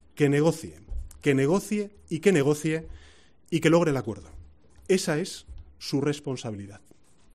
En una rueda de prensa en Ferraz, Sánchez ha subrayado que los agentes de las fuerzas de seguridad no son los responsables de esas imágenes, que son el "epílogo" de la mala gestión de Mariano Rajoy y de Carles Puigdemont ante el problema territorial en Cataluña.